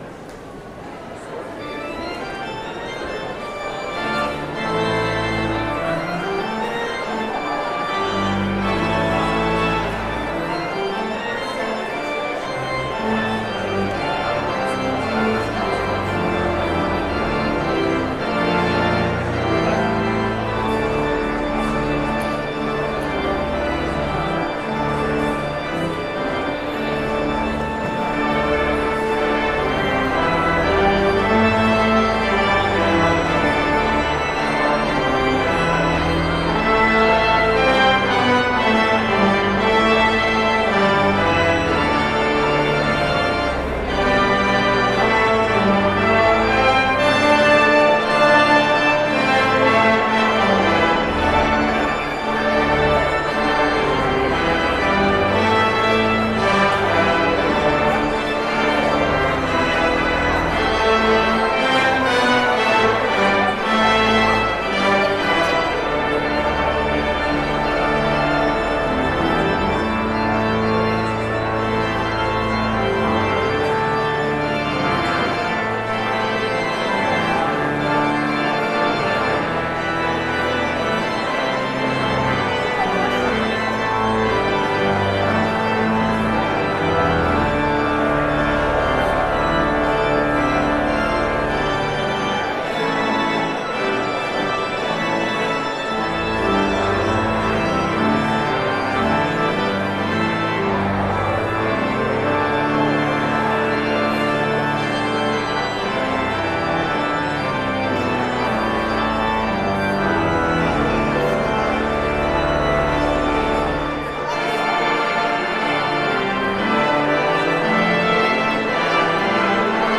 brass
flute and viola
Postlude-What-Wondrous-Love-Is-This-1.mp3